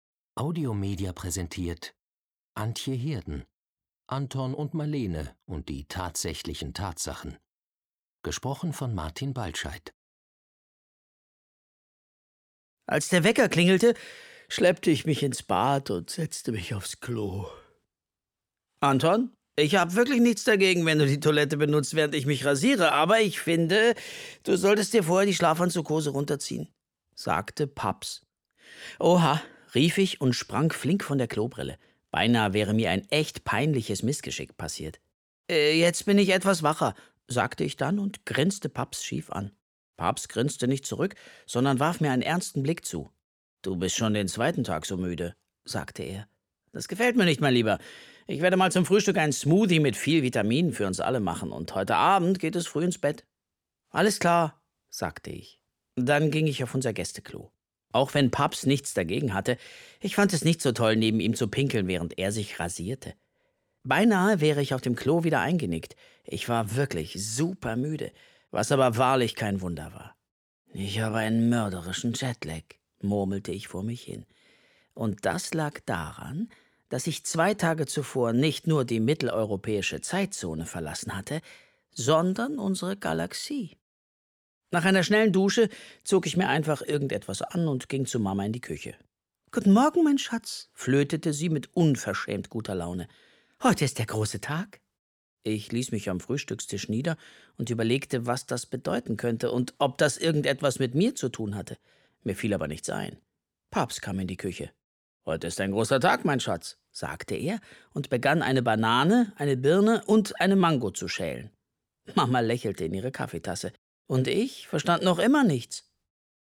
Hörbuch; Lesung für Kinder/Jugendliche